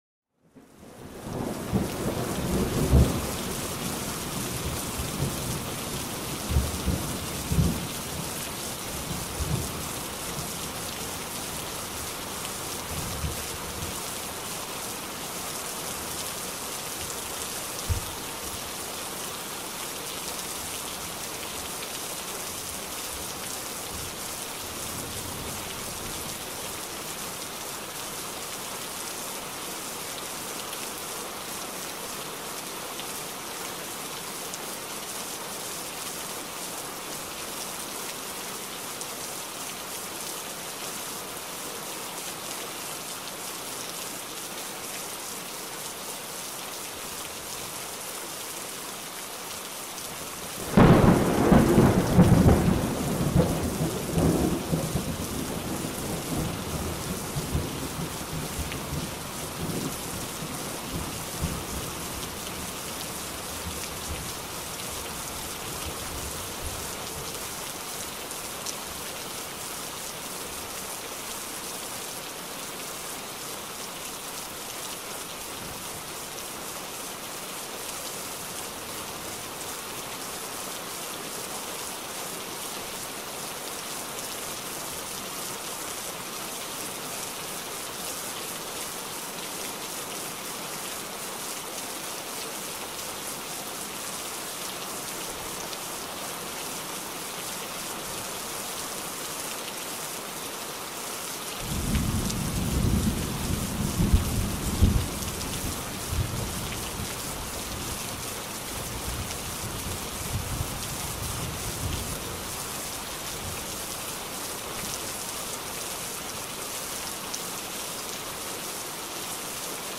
orage-pluie.mp3